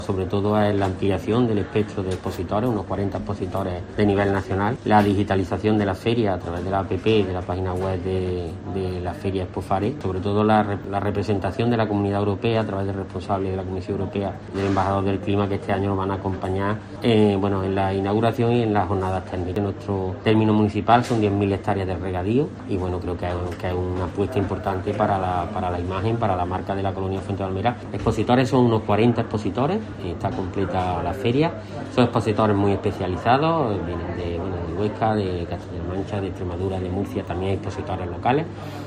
Escucha a Dolores Amo, presidenta de IPRODECO